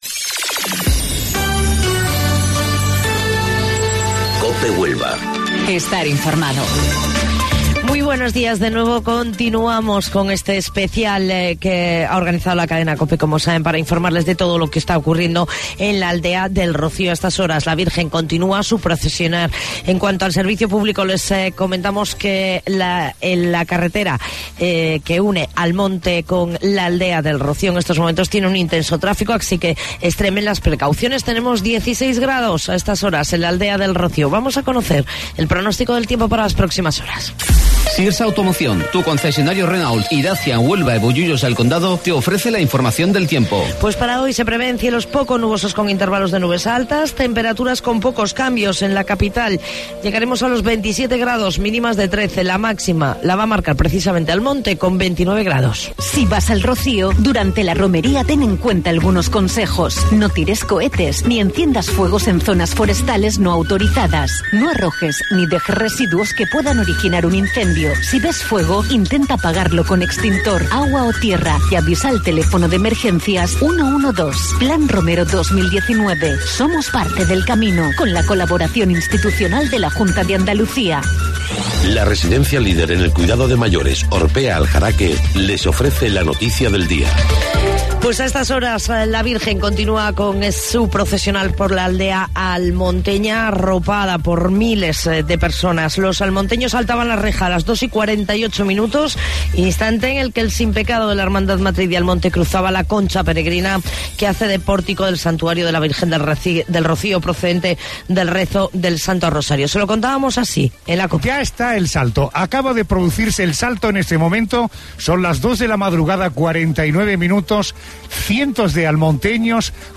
AUDIO: Informativo Local 08:25 del 10 de Junio